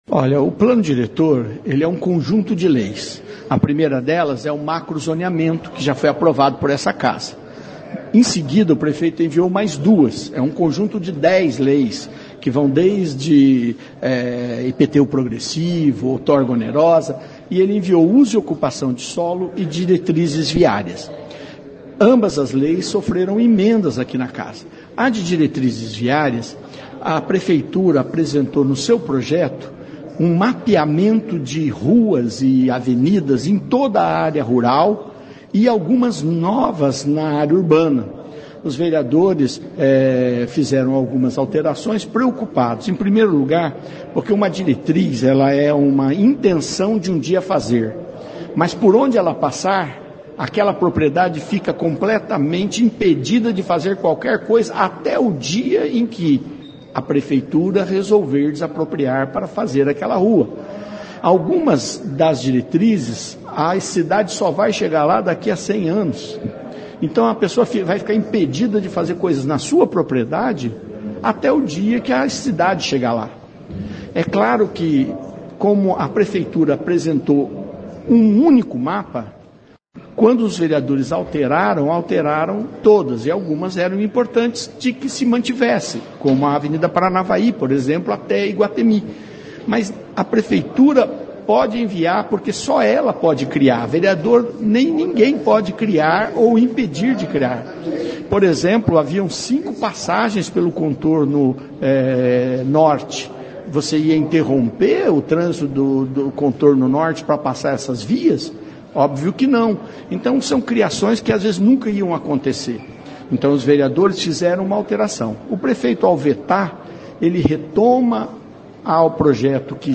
O vereador Sidnei Telles explica: